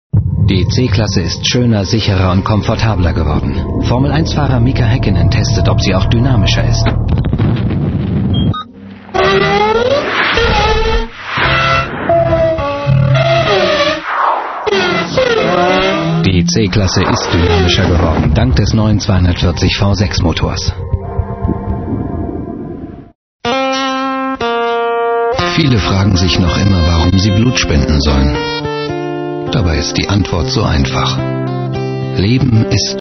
deutscher Synchronsprecher.
Kein Dialekt
Sprechprobe: Industrie (Muttersprache):